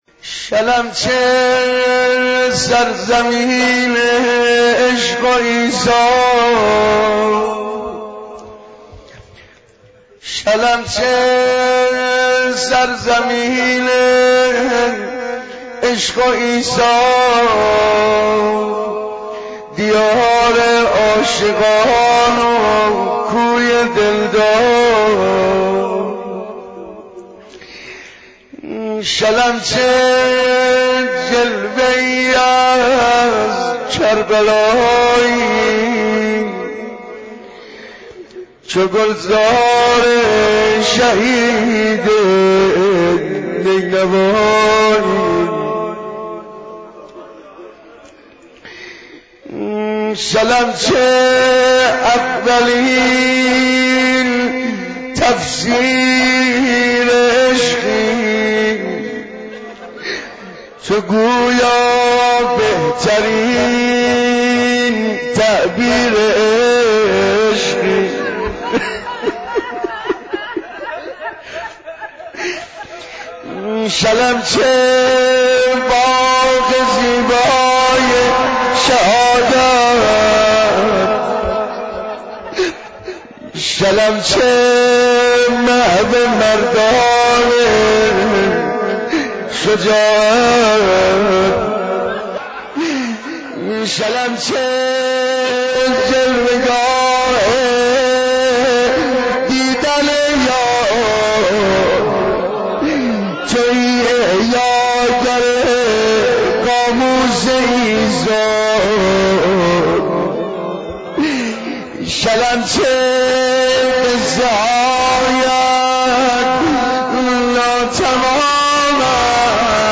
مداحی صوت